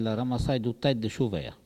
Mémoires et Patrimoines vivants - RADdO est une base de données d'archives iconographiques et sonores.
Localisation Saint-Urbain
Catégorie Locution